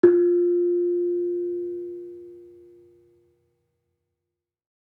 Kenong-resonant-F3-f.wav